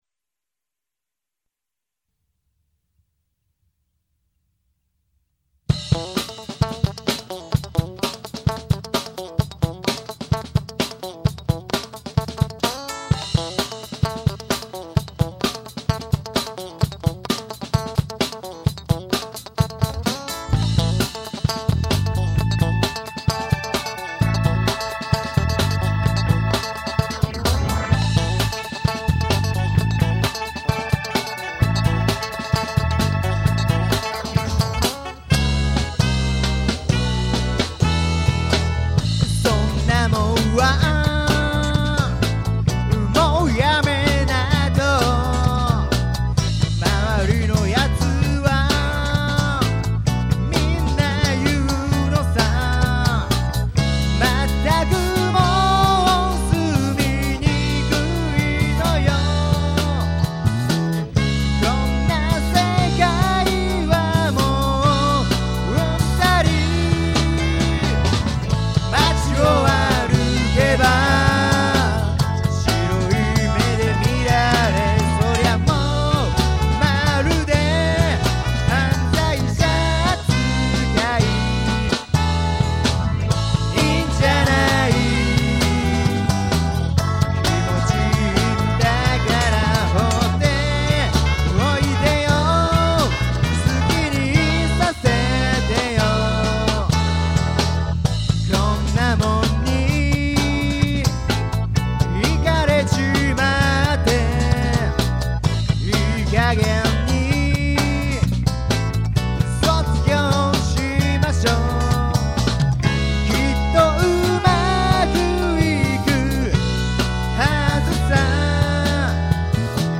特に黒人音楽が主になっているので、難しいところである。
オリジナル曲
スタジオ録音